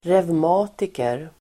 Ladda ner uttalet
Uttal: [revm'a:tiker]